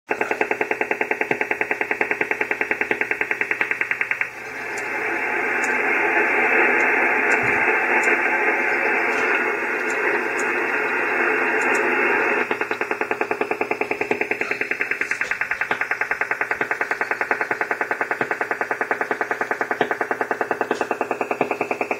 Вы можете прослушать и скачать записи работы станции: гул генераторов, щелчки антенн и другие технические шумы.
Звук дуги ЗГРЛС зафиксированный в 2014 году